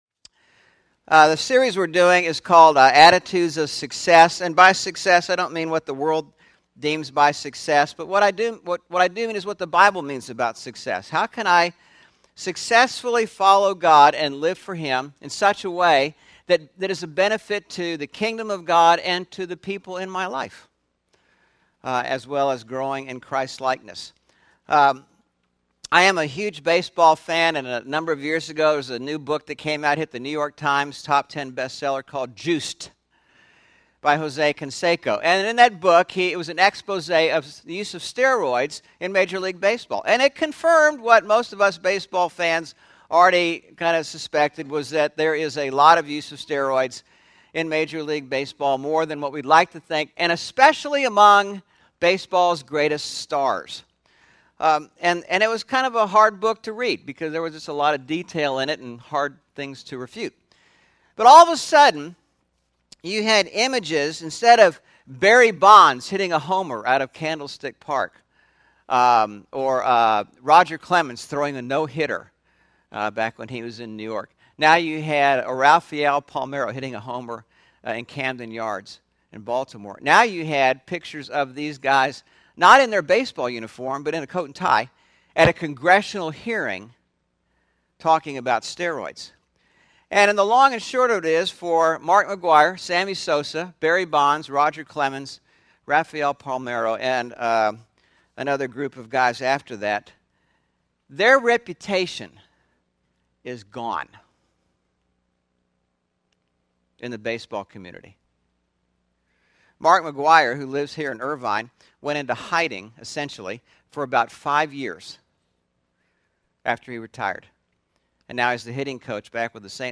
10/9/11 Sermon (Attitudes for Success) – Churches in Irvine, CA – Pacific Church of Irvine